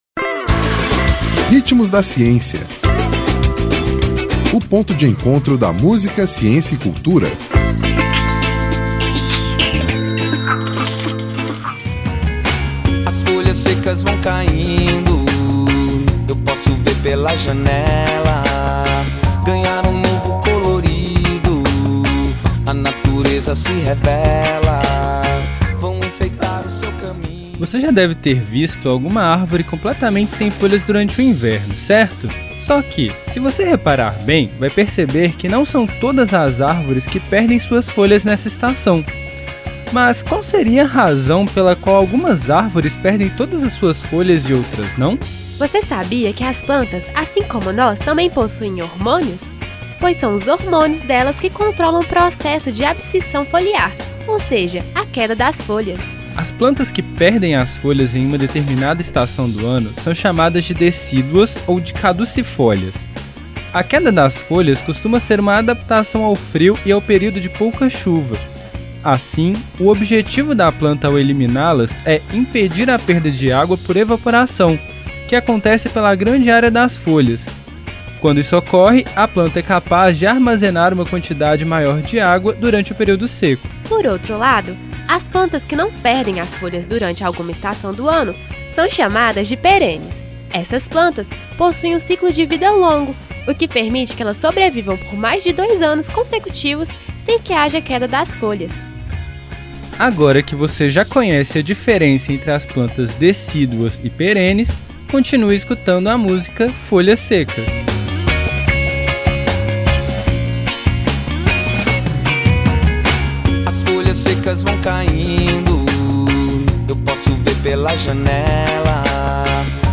Intérprete: Maskavo